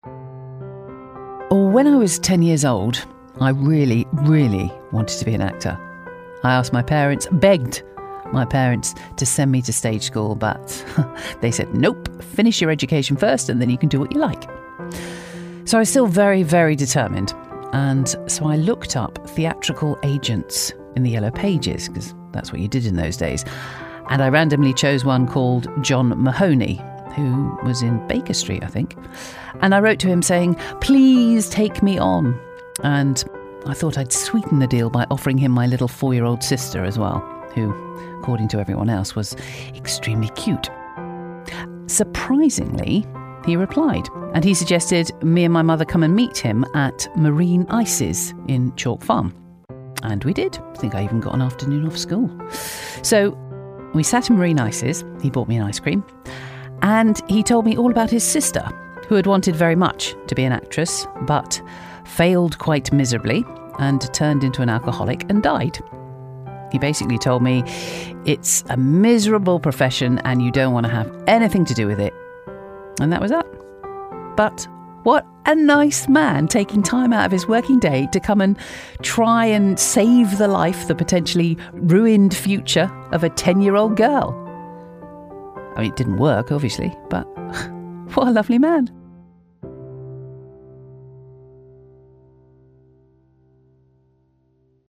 Warm, Confident, Persuasive British-English Female Voice Over
Natural Conversational Voice Over
ULTRA NATURAL – Scripted Conversational Anecdote-Style